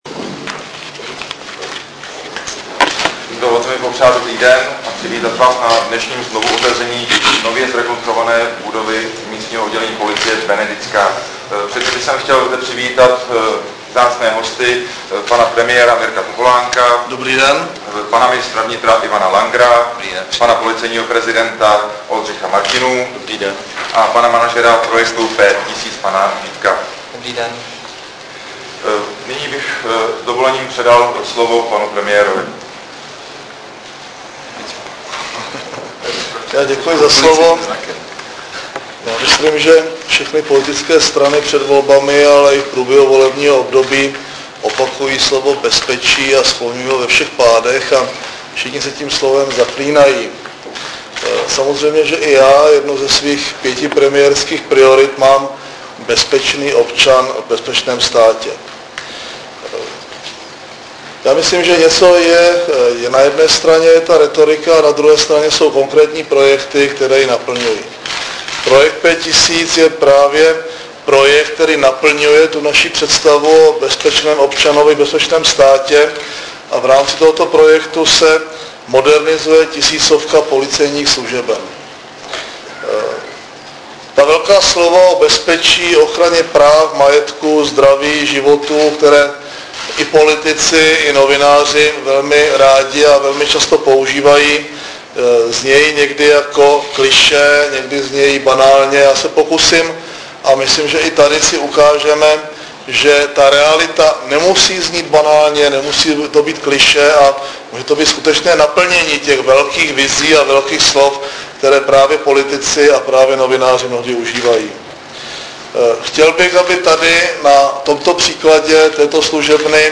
Projev premiéra Mirka Topolánka při slavnostním otevření rekonstruované policejní služebny MO Policie ČR Benediktská dne 6. 2. 2008